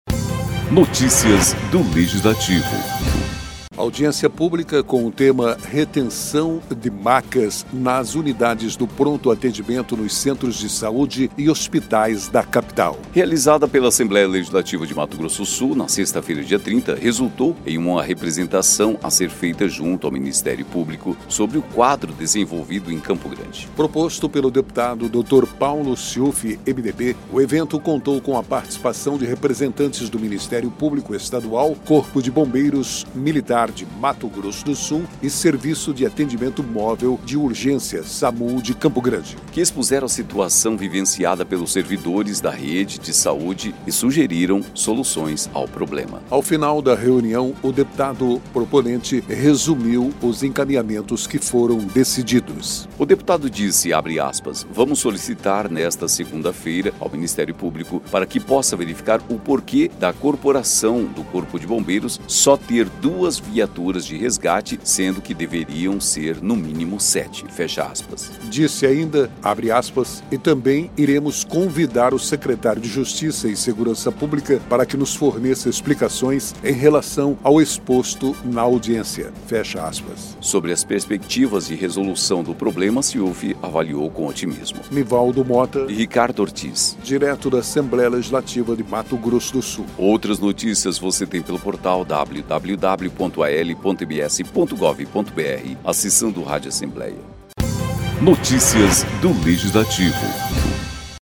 A audiência pública com o tema “Retenção de Macas nas Unidades de Pronto Atendimento nos Centros de Saúde e Hospitais da Capital”, realizada pela Assembleia Legislativa de Mato Grosso do Sul nesta sexta-feira (30), resultou em uma representação a ser feita junto ao Ministério Público sobre o quadro desenvolvido em Campo Grande.